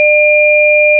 sin_stereo.wav